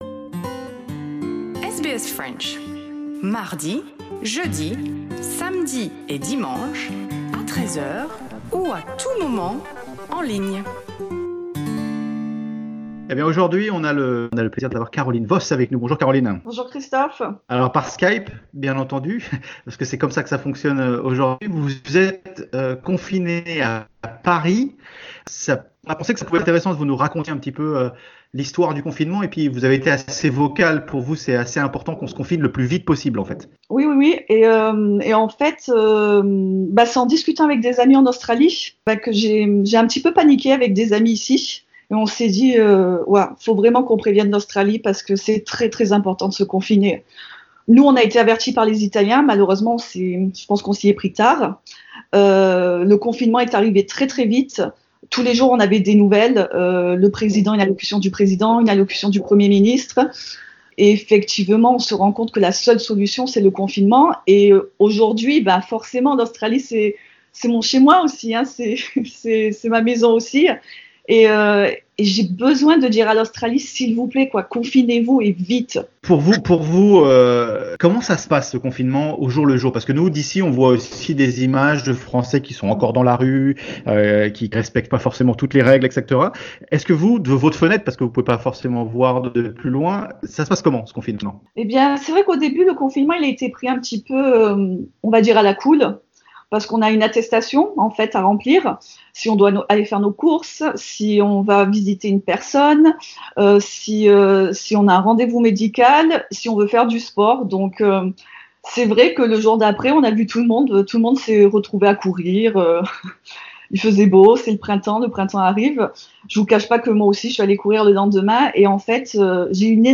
Elle est en ce moment à Paris, confinée pour cause de Coronavirus, et elle nous en parle, via Skype.